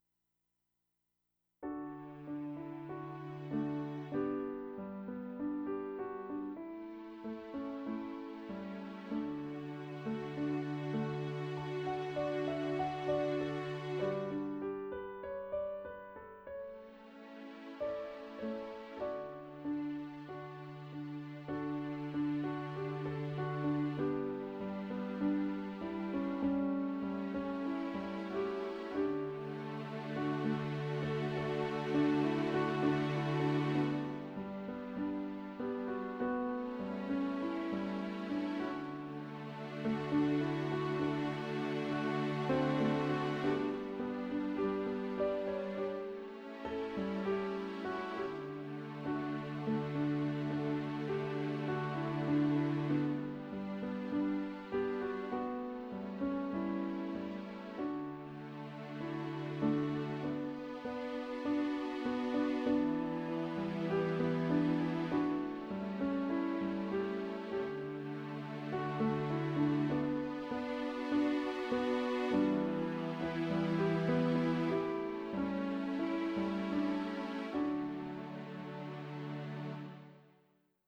Im JETZT leben lernen Meditation